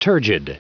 Prononciation du mot turgid en anglais (fichier audio)
Prononciation du mot : turgid